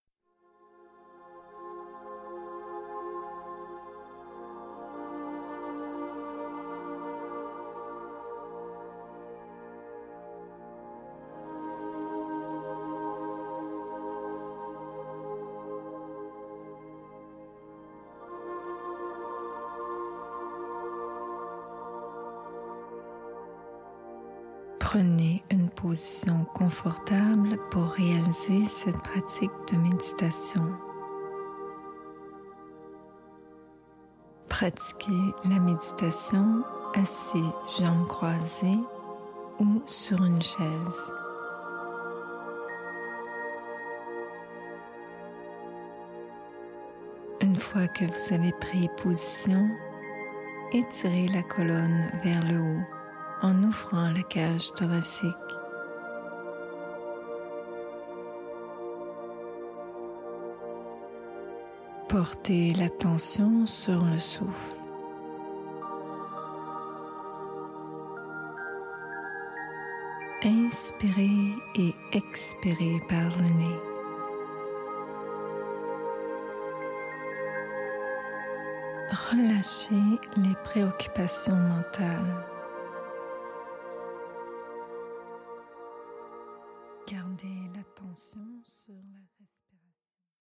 Une session guidée de relaxation / méditation comprenant assouplissements, pranayama, mantra OM et méditation guidée en lien avec le chakra du coeur.
relaxation-meditation.mp3